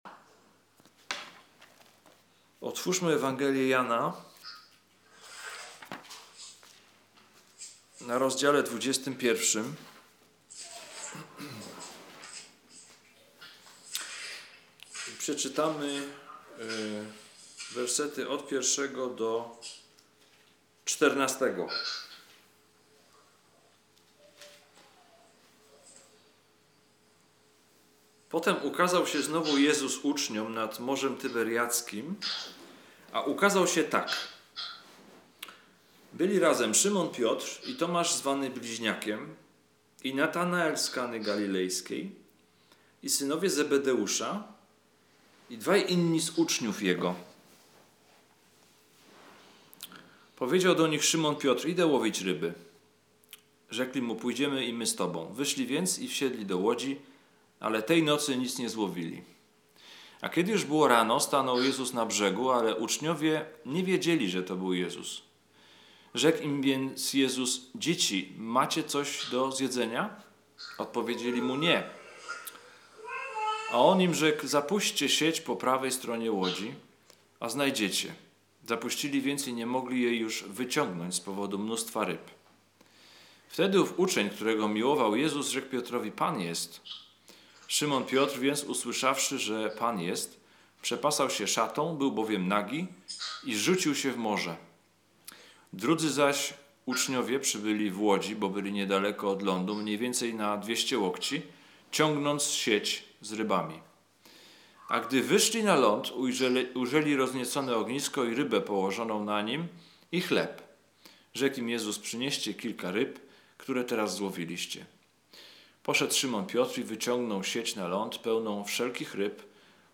Ulica Prosta - Kazania z 2015